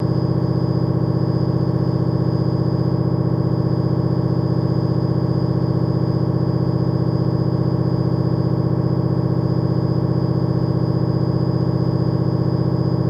MRI Test Small Machine Test